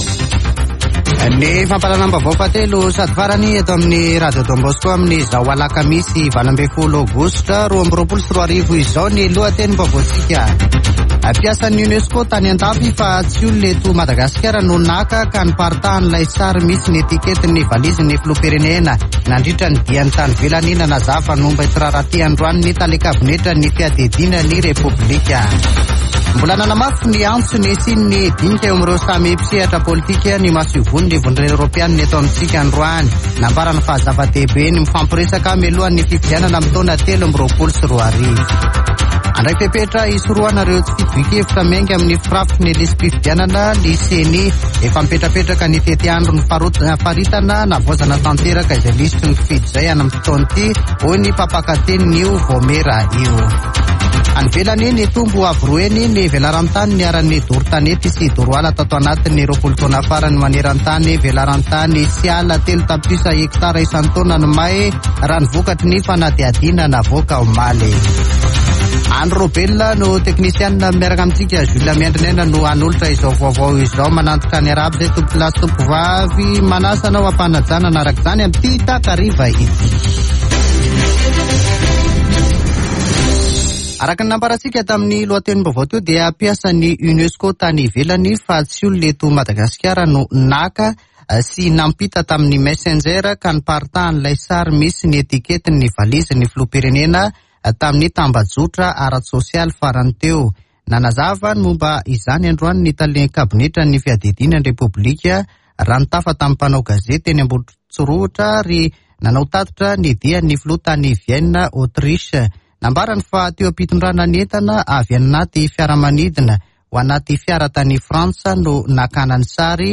[Vaovao hariva] Alakamisy 18 aogositra 2022